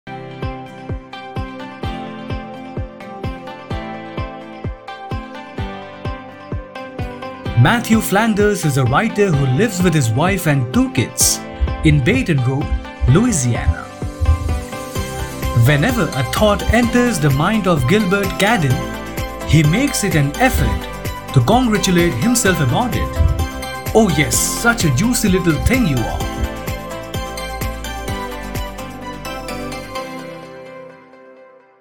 As The Day Goes By (Audio book)
Trailer